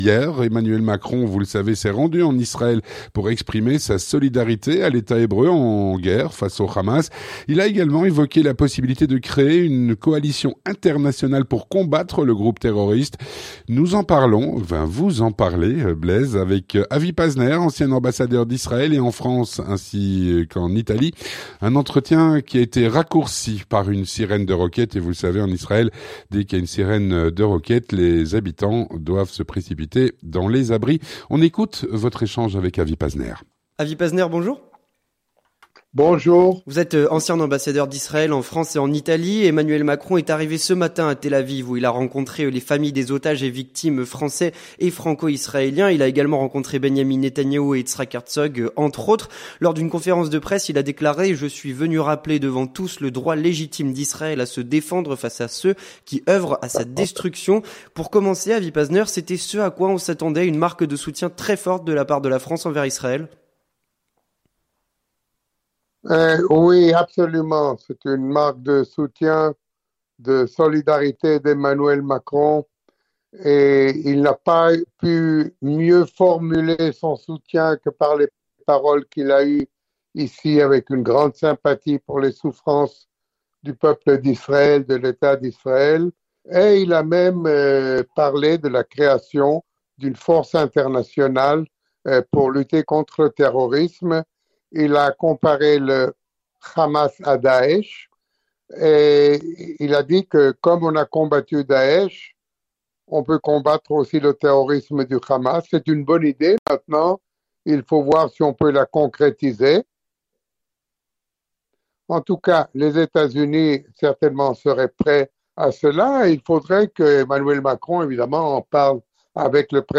Un entretien raccourci par une sirène de roquette…
Avec Avi Pazner, Ancien ambassadeur d'Israël en France et en Italie